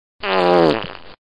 Sound Effects
Wet-Fart